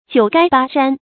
九垓八埏 jiǔ gāi bā yán
九垓八埏发音